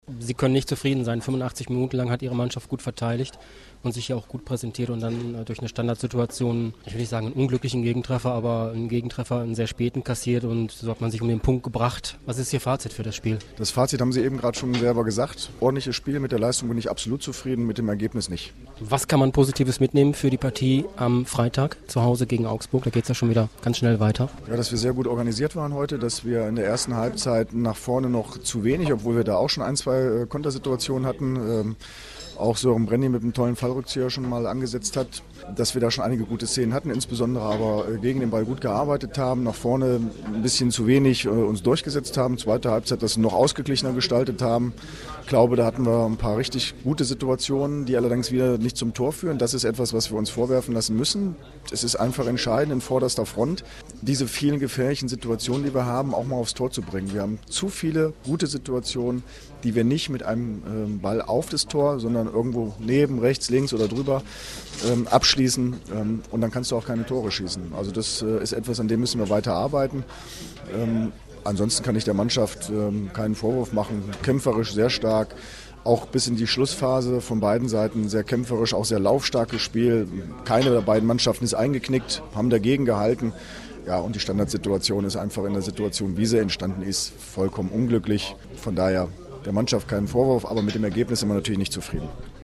AUDIOKOMMENTAR
Chef-Trainer Andre Schubert zum Spiel